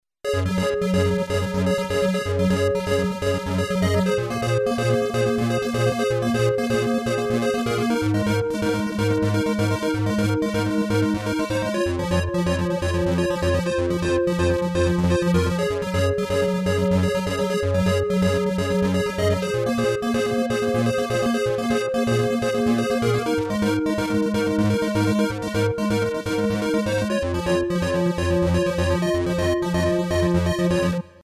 synth
synth.mp3